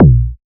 edm-kick-33.wav